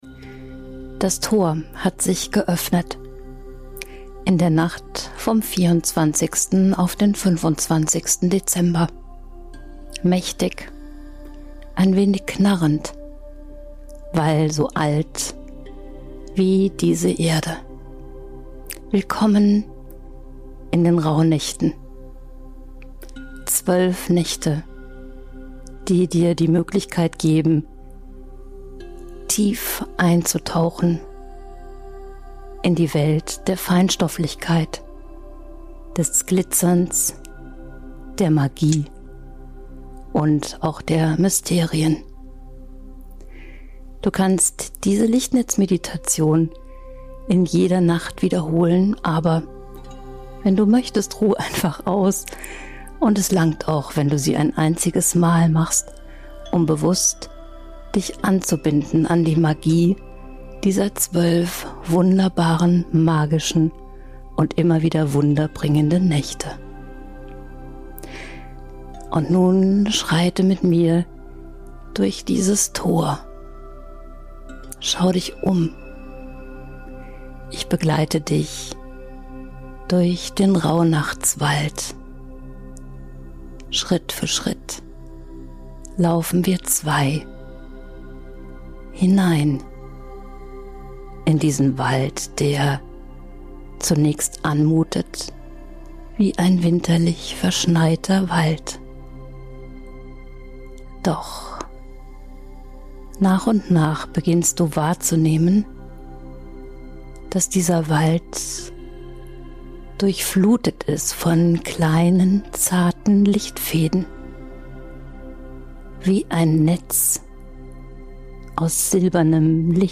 Diese Meditation ist mystisch, transformierend und voller Rauhnachtszauber.